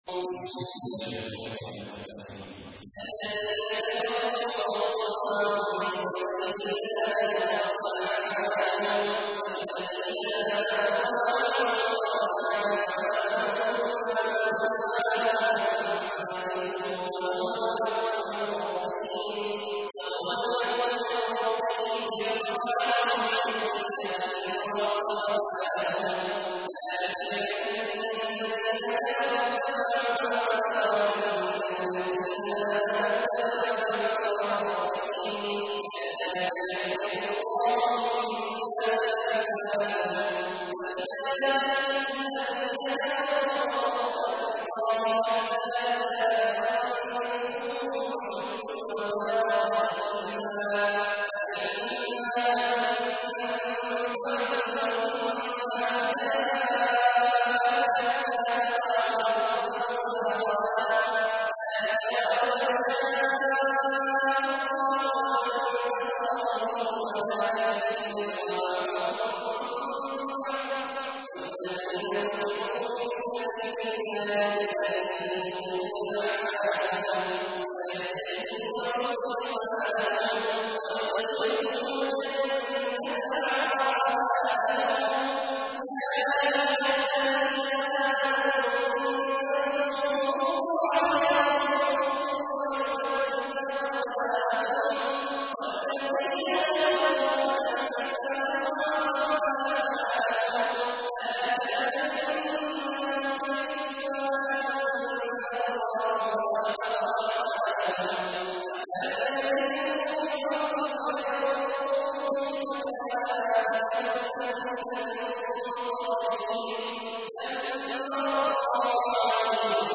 تحميل : 47. سورة محمد / القارئ عبد الله عواد الجهني / القرآن الكريم / موقع يا حسين